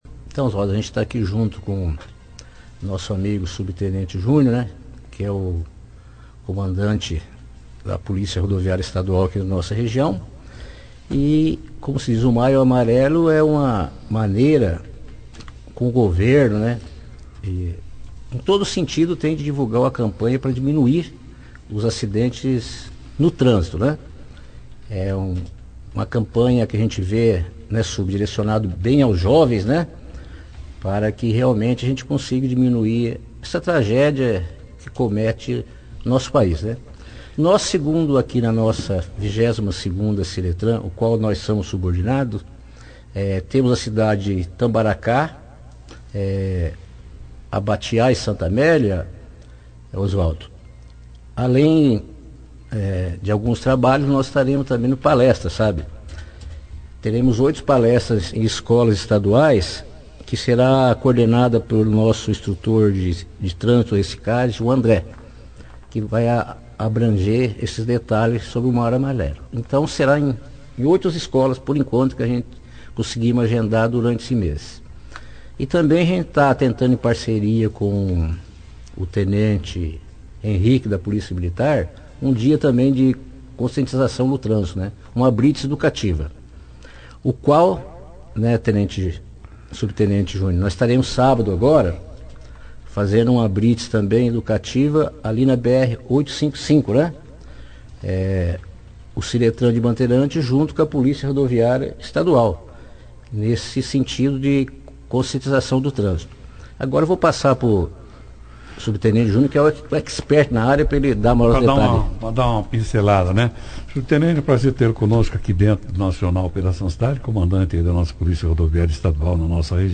participaram da 2ª edição do Jornal Operação Cidade desta quarta-feira, 08/05/19, que falaram sobre a campanha que envolve o Comitê Trânsito Seguro, que reúne Detran-Paraná, Batalhão de Trânsito da Polícia Militar (BPTran), Polícia Rodoviária Estadual (BPRV), PRF, secretarias municipais de trânsito e o Observatório Nacional de Segurança Viária.